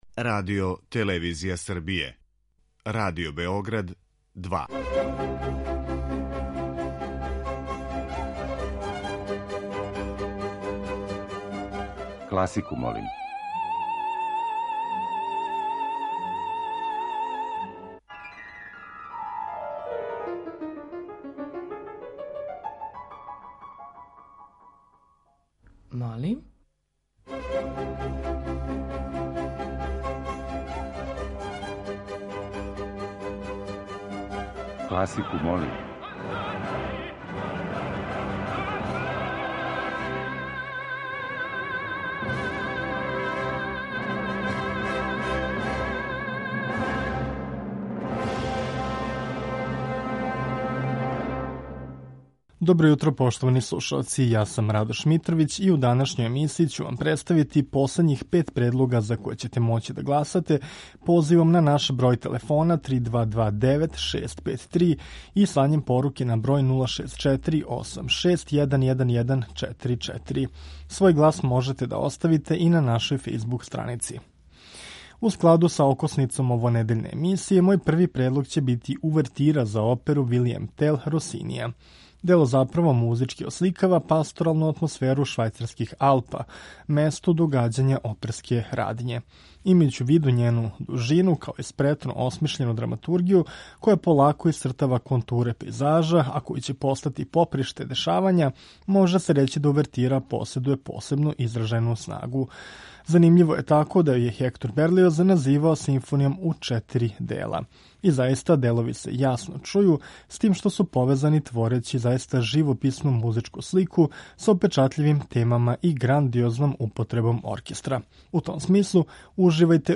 У емисији Класику, молим ове недеље окосница ће нам бити увертире познатих или мање познатих оперских остварења.